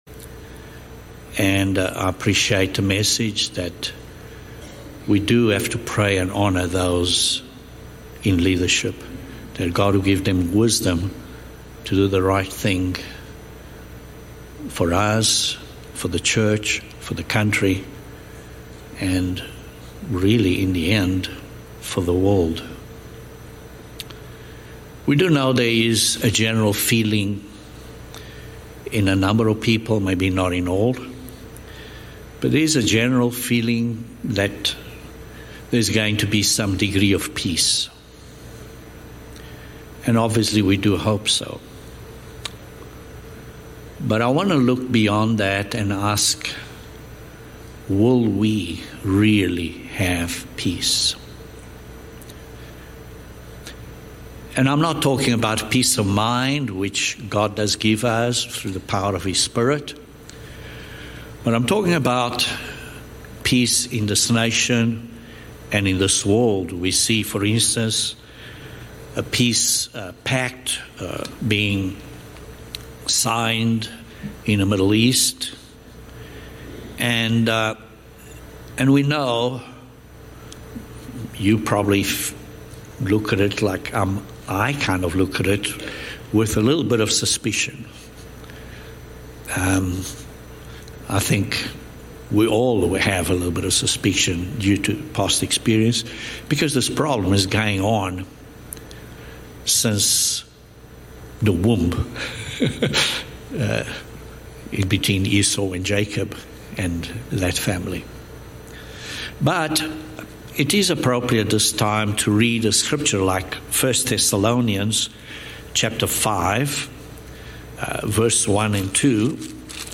This sermon addresses the war between Satan and Israel. There will be no peace until Satan is cast out of heaven.